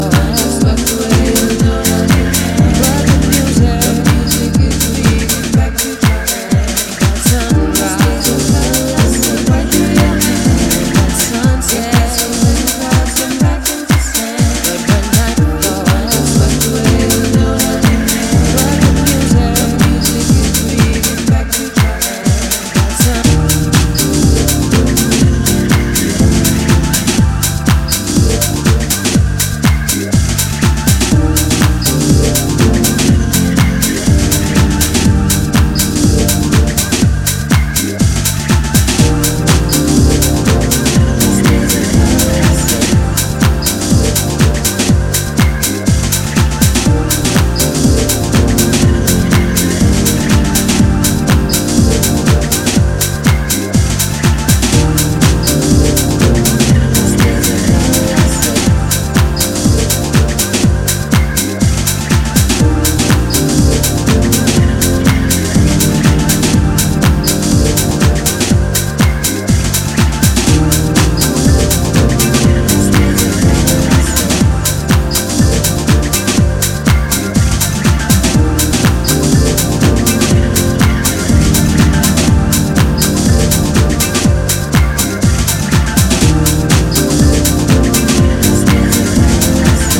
ジャンル(スタイル) DEEP HOUSE / SOULFUL HOUSE